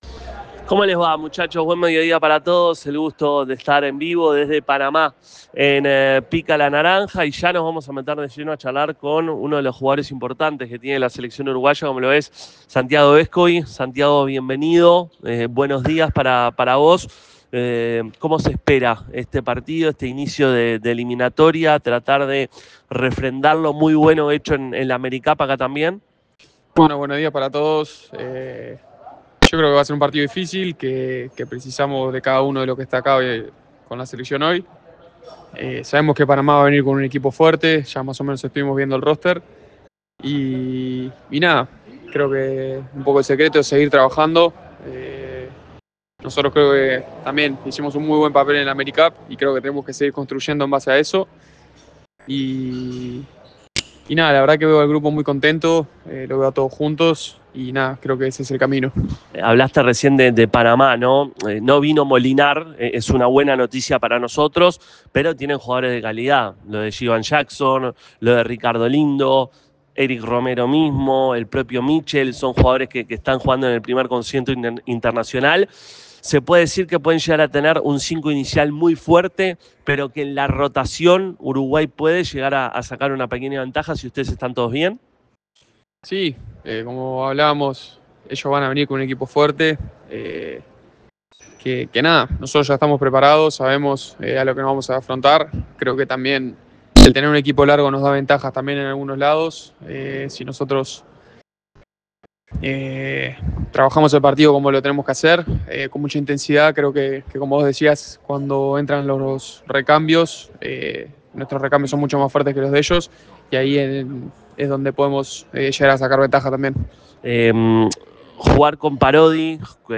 Santiago Vescovi habló con Pica La Naranja previo al juego de mañana ante Panamá.